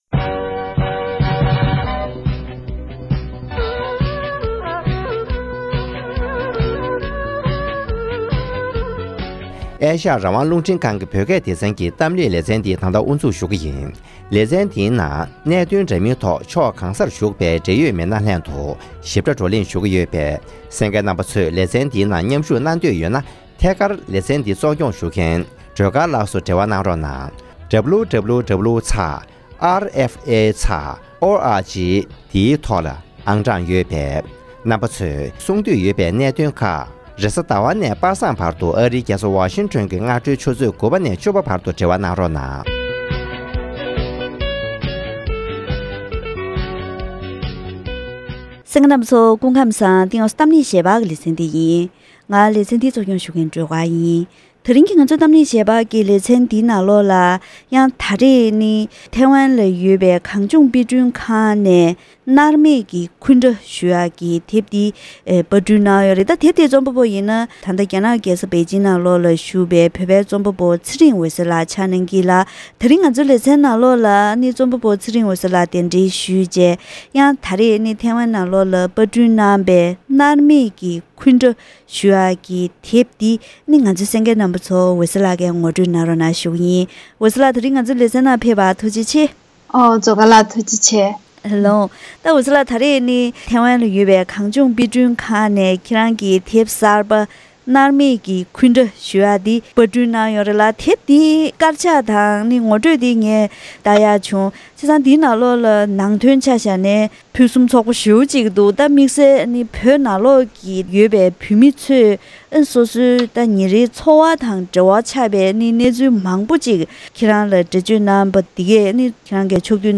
རྩོམ་པ་པོ་འོད་ཟེར་ལགས་དང་བཀའ་མོལ་ཞུས་པ་ཞིག་གསན་རོགས་གནང་།།